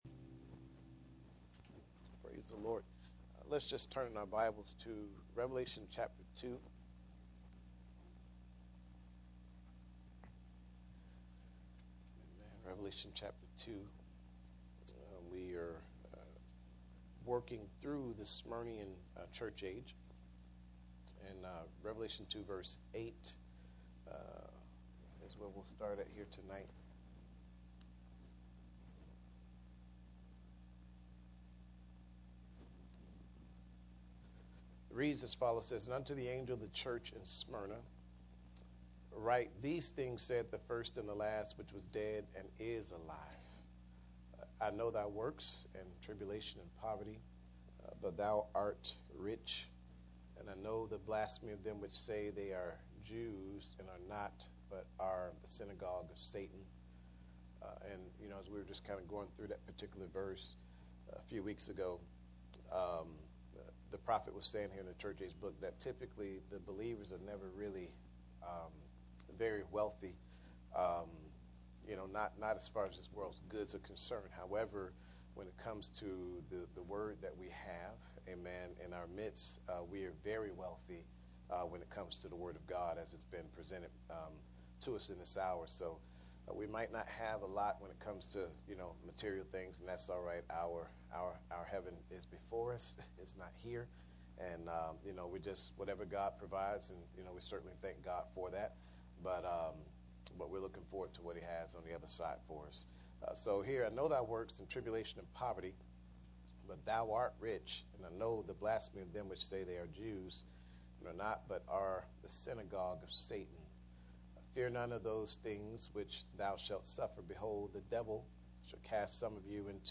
Series: Bible Study Passage: Revelation 2:8-11 Service Type: Midweek Meeting %todo_render% « Lean Not Unto Thy Own Understanding He Conquered